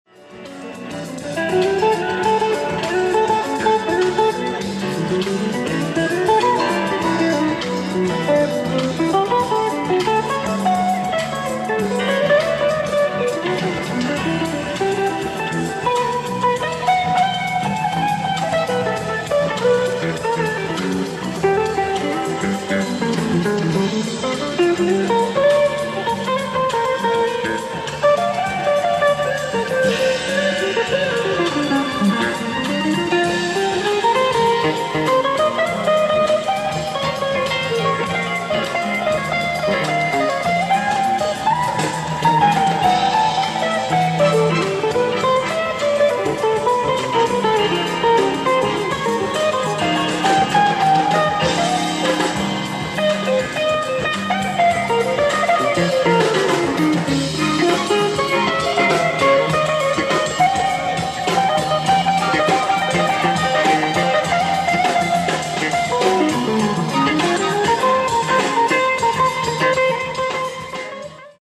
ライブ・アット・ハッチ・メモリアル・シェル、ボストン 06/24/1990
オーディエンス録音ながらテープの保存状態が良かったのか非常に良好な音質で最後までお聴き頂けます。